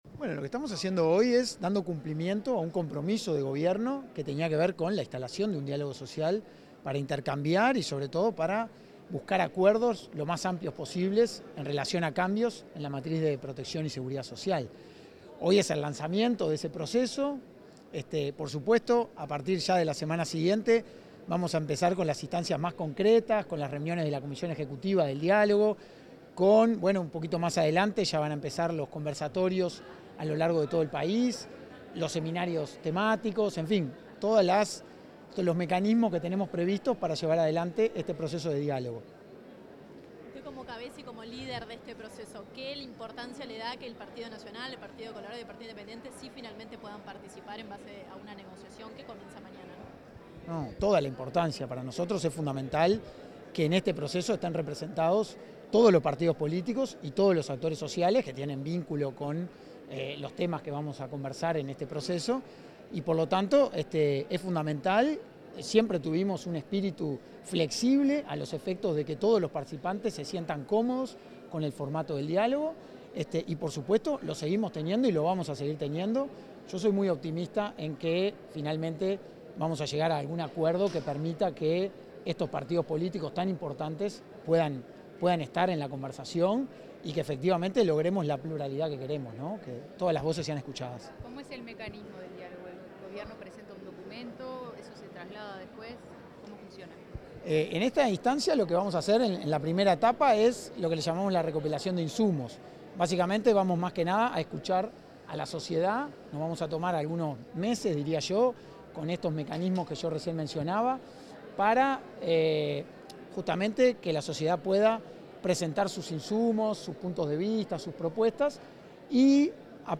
Declaraciones del coordinador del Comité Ejecutivo de la Comisión Sectorial de Protección y Seguridad Social, Hugo Bai 17/07/2025 Compartir Facebook X Copiar enlace WhatsApp LinkedIn El coordinador del Comité Ejecutivo de la Comisión Sectorial de Protección y Seguridad Social, Hugo Bai, dialogó con la prensa en el lanzamiento del Diálogo Social en el Auditorio del Sodre.